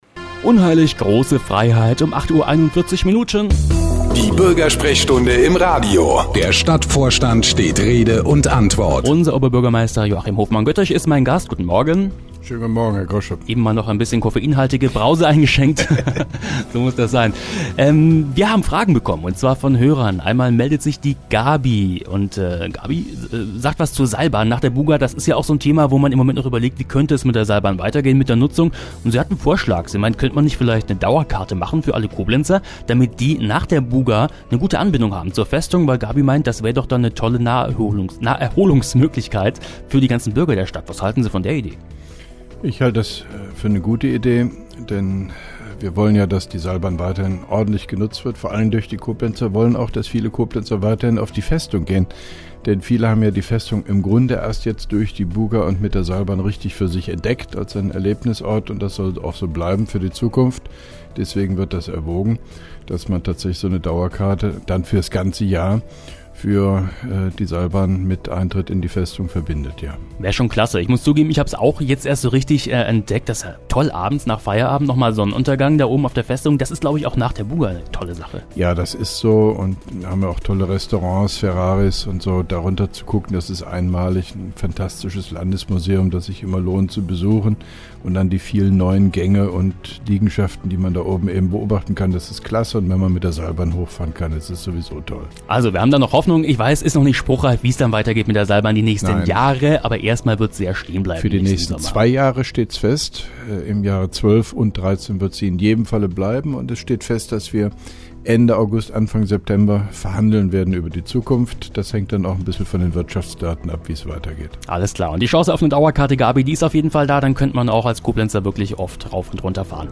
(2) Koblenzer Radio-Bürgersprechstunde mit OB Hofmann-Göttig 26.07.2011